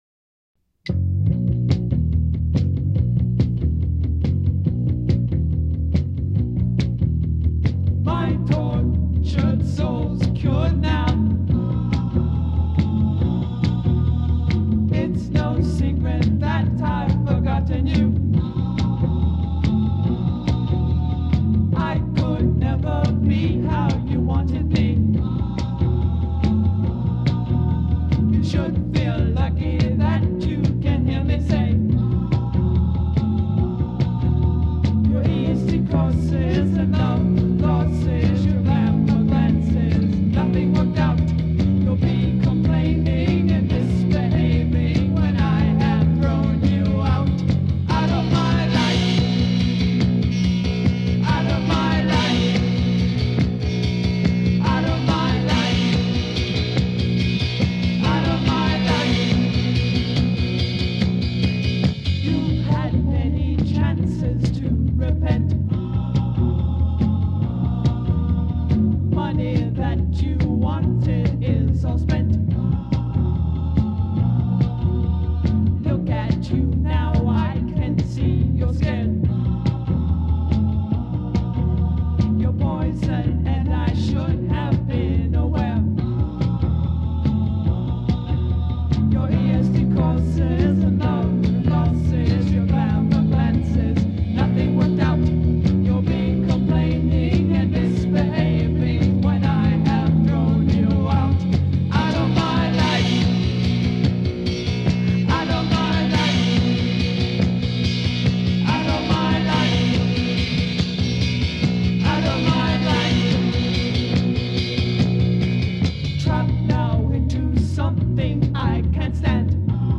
in the land of Tucson punk rock